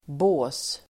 Uttal: [bå:s]